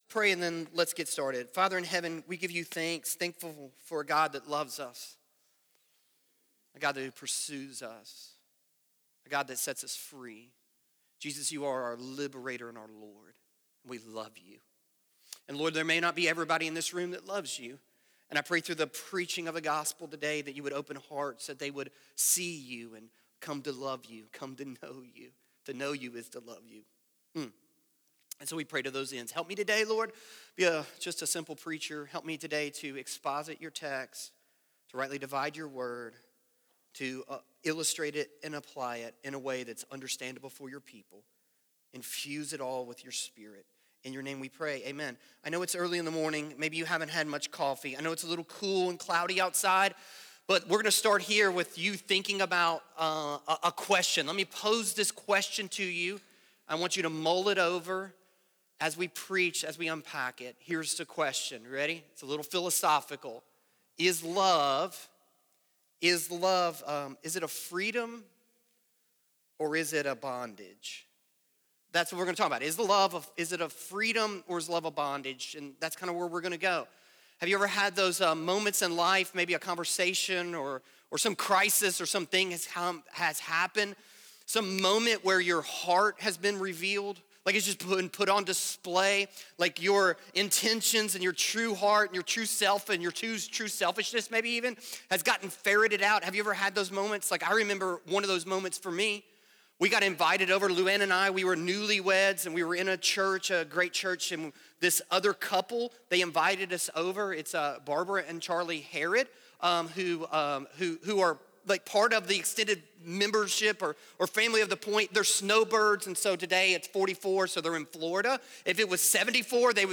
Share Sermon